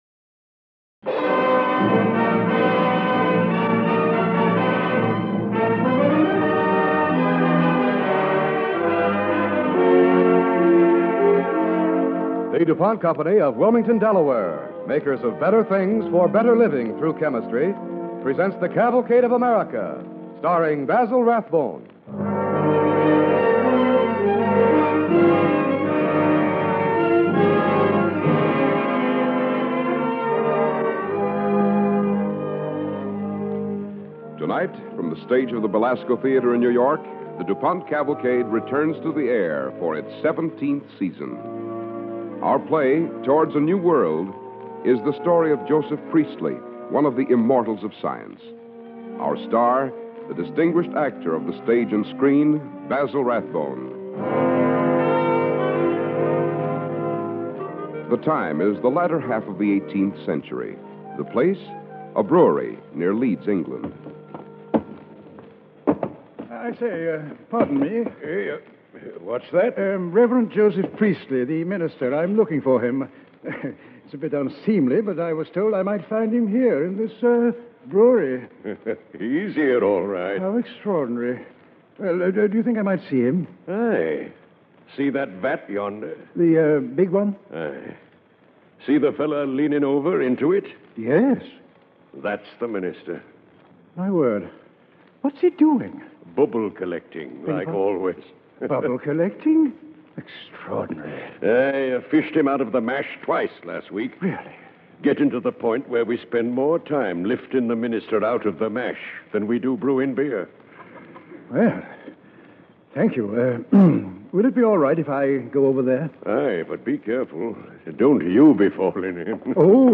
Towards a New World, starring Basil Rathbone and Alice Frost
Cavalcade of America Radio Program